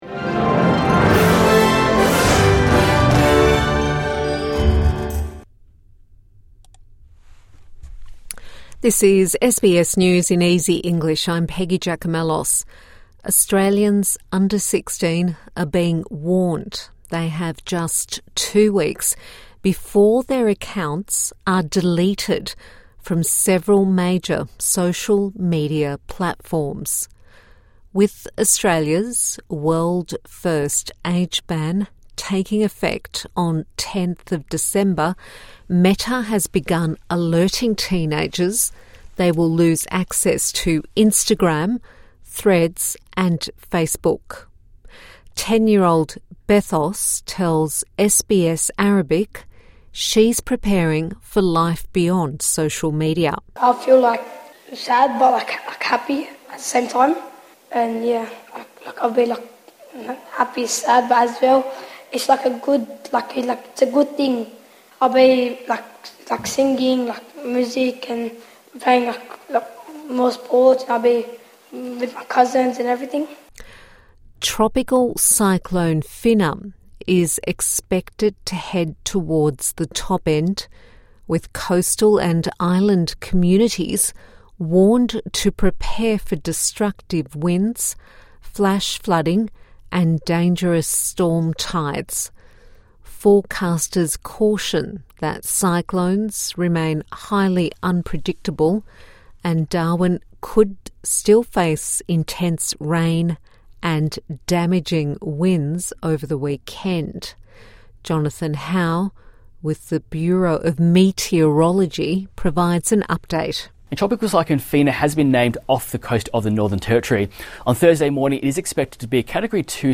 A daily 5 minute news wrap for English learners and people with disability.